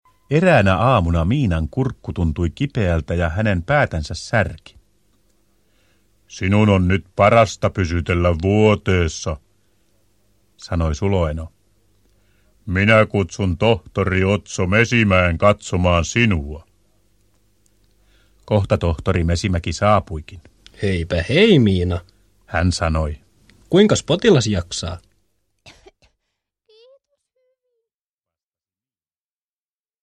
Miina ja Manu lääkärissä – Ljudbok – Laddas ner